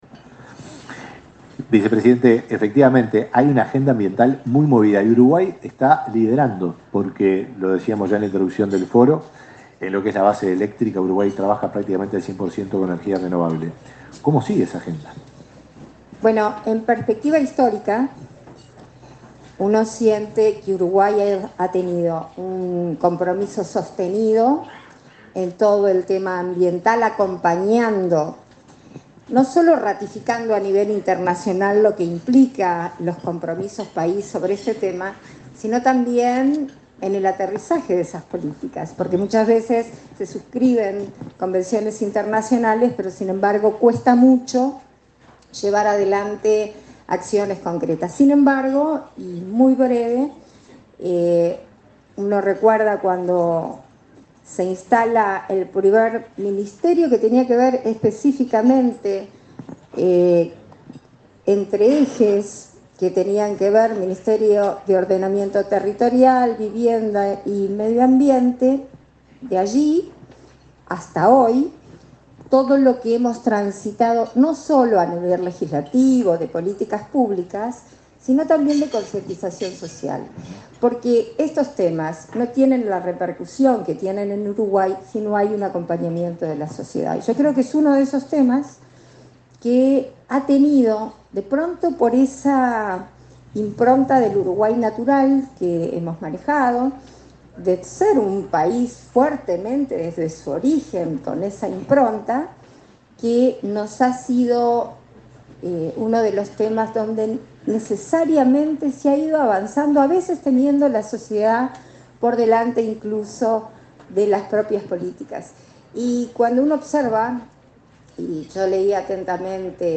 Palabras de la vicepresidenta de la República, Beatriz Argimón
Palabras de la vicepresidenta de la República, Beatriz Argimón 16/06/2022 Compartir Facebook X Copiar enlace WhatsApp LinkedIn La vicepresidenta de la República, Beatriz Argimón, participó este jueves 16 en el Club de Golf, del V Foro Económico, que trató sobre la descarbonización de la economía y las oportunidades relativas al hidrógeno verde y sus derivados.